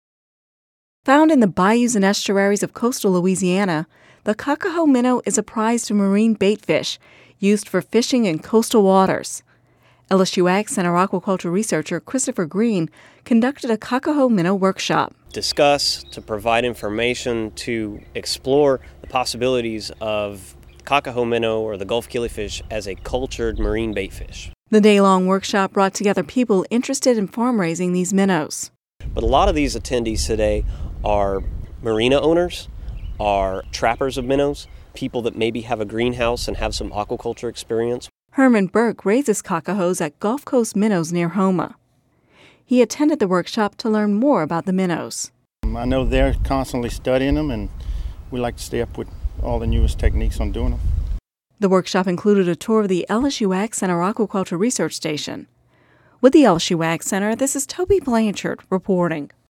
(Radio News 11/01/10) Found in the bayous and estuaries of coastal Louisiana, the cocahoe minnow is a prized marine bait fish used for fishing in coastal waters.